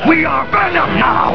From the Spider-Man animated series.